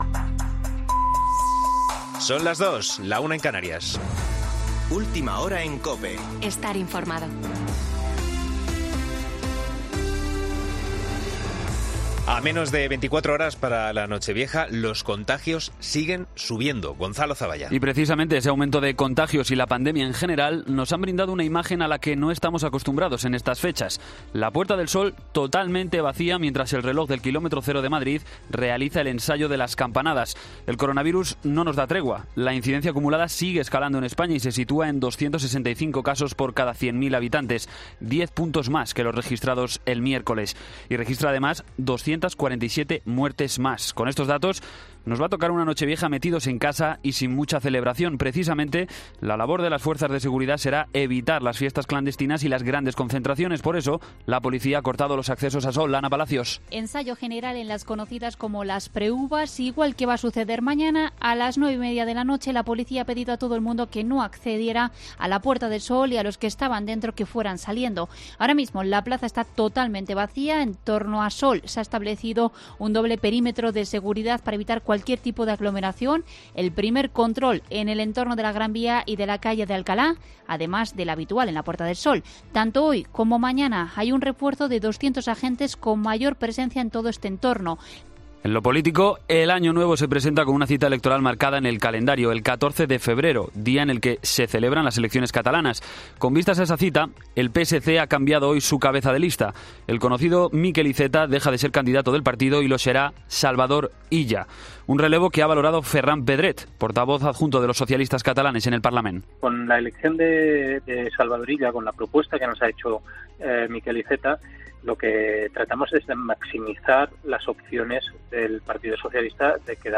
Boletín de noticias COPE del 31 de diciembre de 2020 a las 02.00 horas